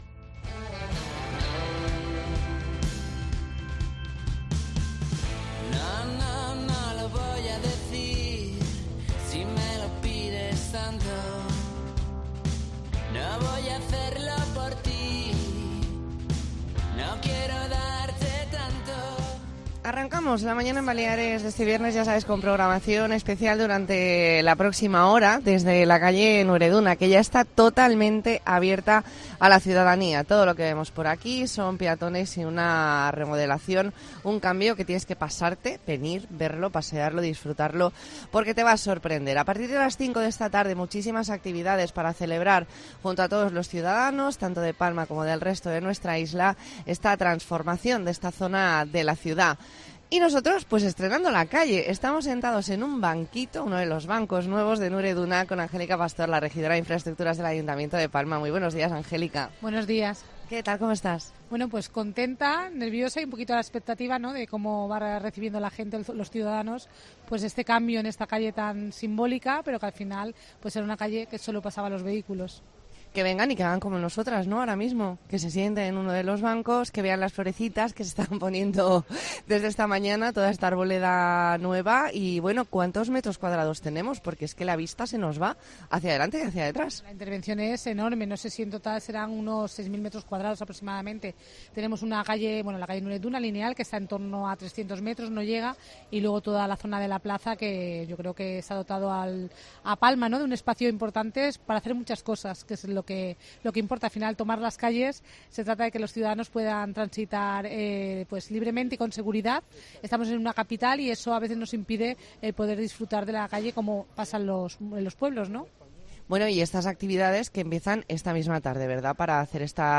Redacción digital Madrid - Publicado el 28 oct 2022, 12:30 - Actualizado 18 mar 2023, 23:18 1 min lectura Descargar Facebook Twitter Whatsapp Telegram Enviar por email Copiar enlace Hablamos con Angélica Pastor, regidora de Infraestructuras del Ayuntamiento de Palma. Entrevista en La Mañana en COPE Más Mallorca, viernes 28 de octubre de 2022.